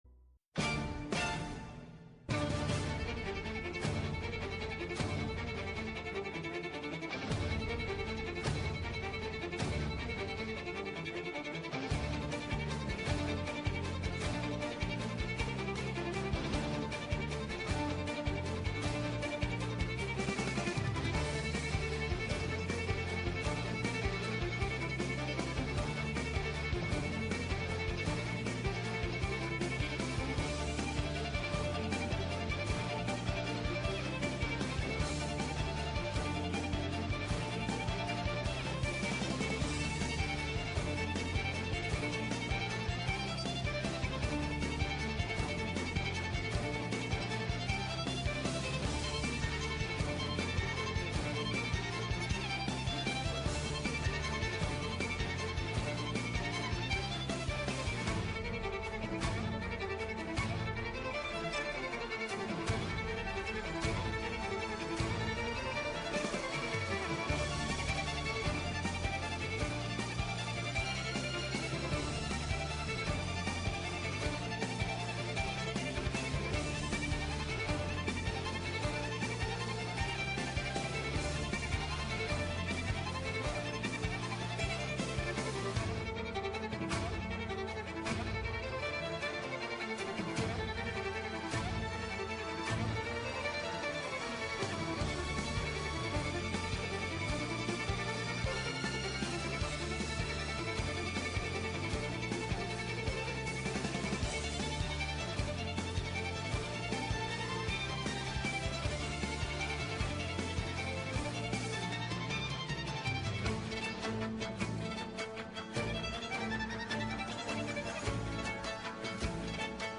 Live στο Studio
Συνεντεύξεις